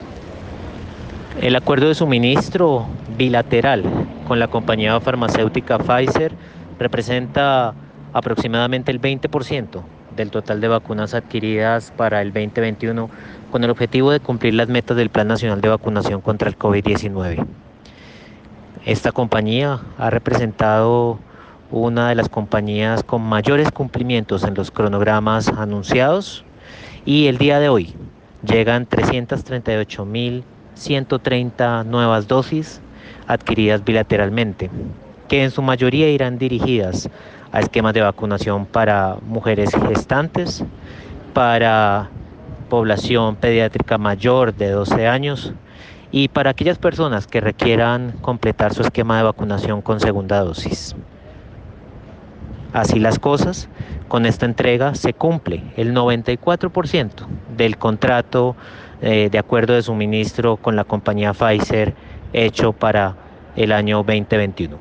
Audio de Germán Escobar, jefe de Gabinete del Ministerio.